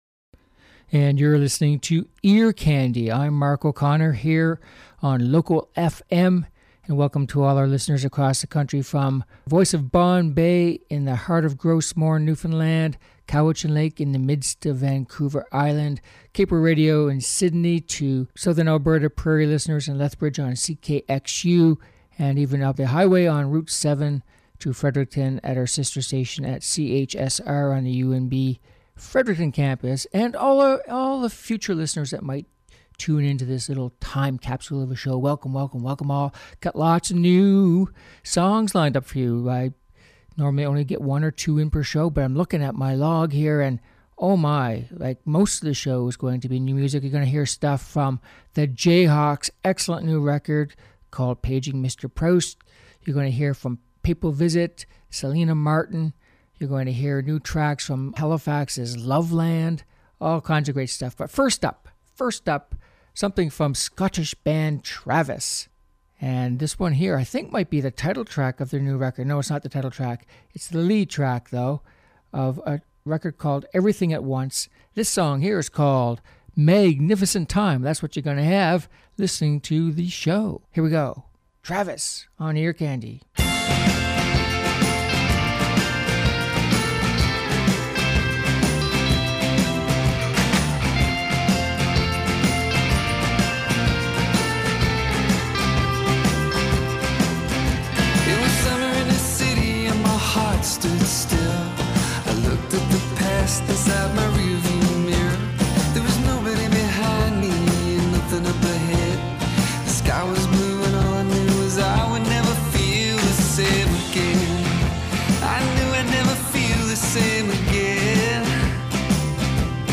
Uptempo Pop and Rock Songs